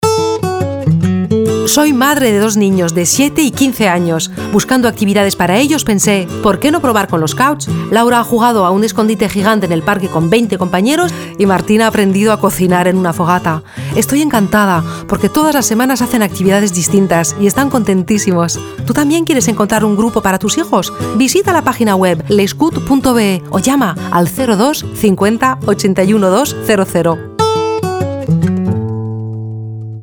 Spot radio espagnol, darija et turc